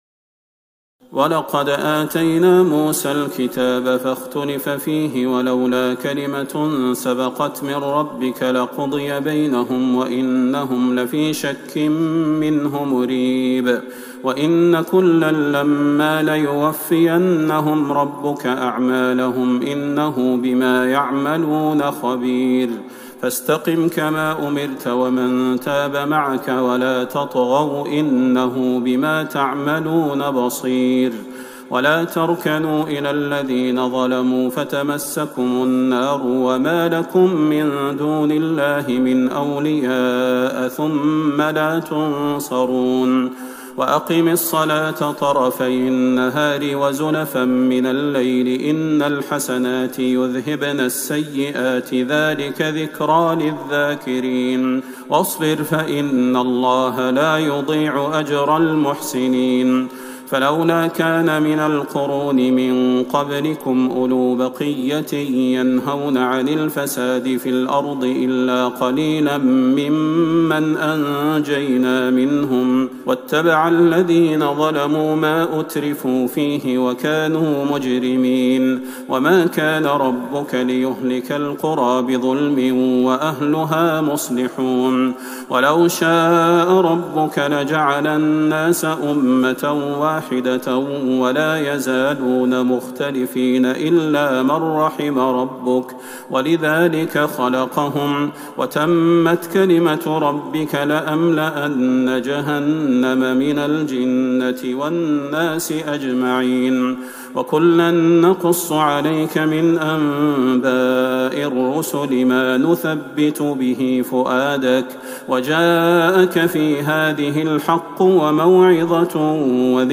ليلة ١٦ رمضان ١٤٤١هـ من سورة هود { ١١٠-١٢٣ } ويوسف { ١-٥٣ } > تراويح الحرم النبوي عام 1441 🕌 > التراويح - تلاوات الحرمين